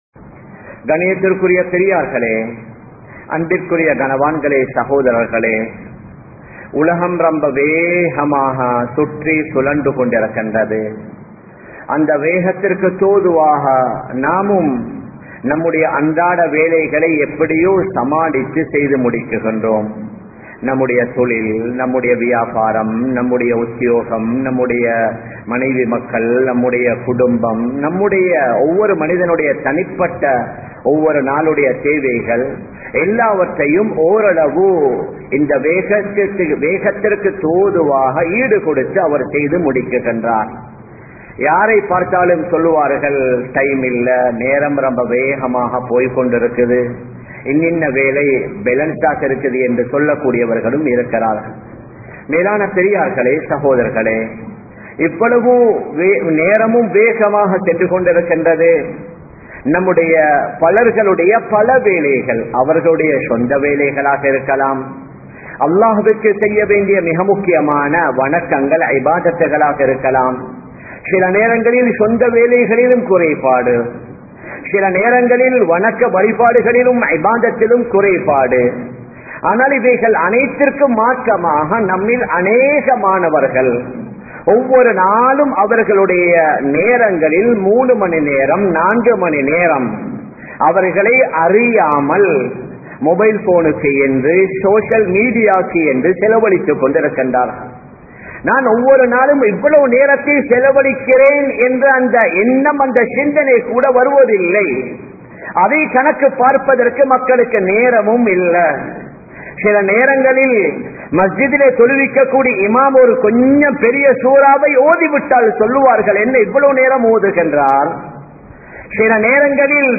Social Mediavaal Seeralium Kudumbangal (சமூக வலையதளங்களால் சீரழியும் குடும்பங்கள்) | Audio Bayans | All Ceylon Muslim Youth Community | Addalaichenai
Samman Kottu Jumua Masjith (Red Masjith)